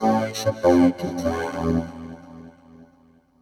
Techno / Voice
1 channel